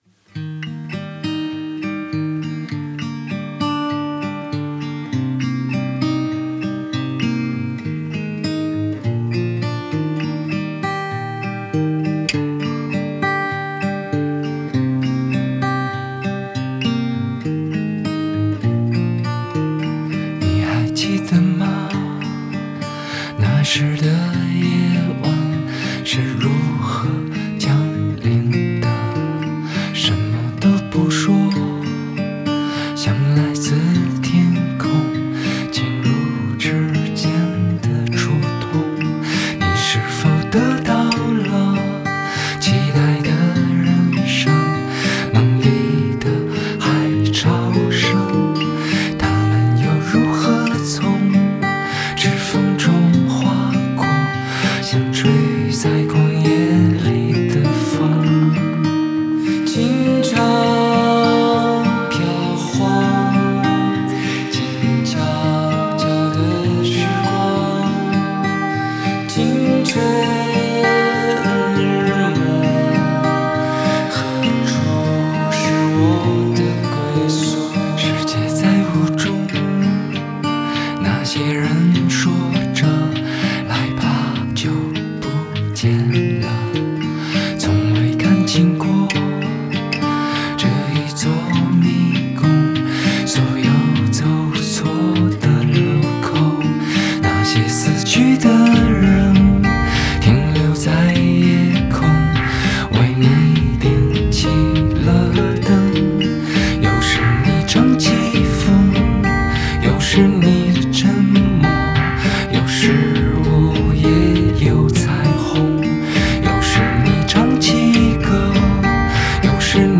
Bass
Accordion
Cello
English Horn
Environment Sound Effect
Guitars
Melodica
Keyboards/Synthesizer